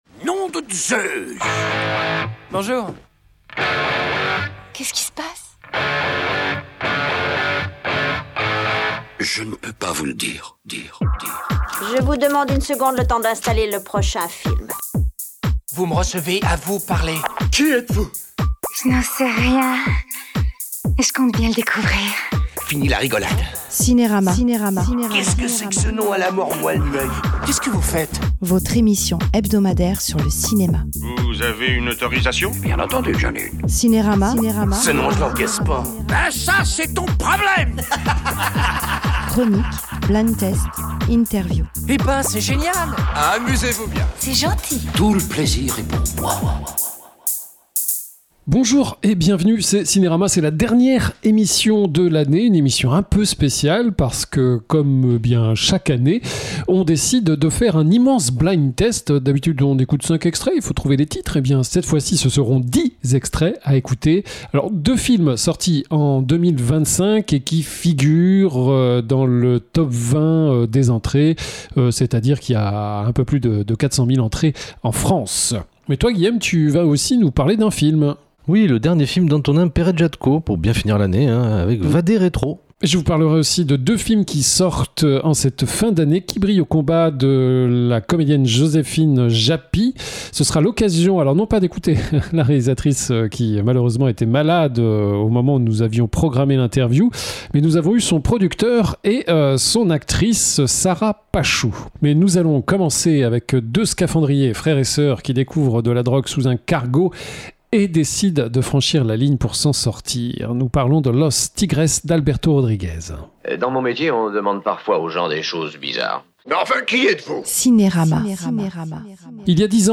LE BLIND TESTComme chaque fois, pour la dernière émission, c’est un blind test sur les films sortis durant l’année avec non pas 5 mais 10 extraits.